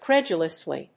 音節cred･u･lous･ly発音記号・読み方kréʤələsli